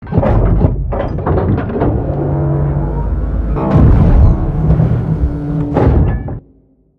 tug.ogg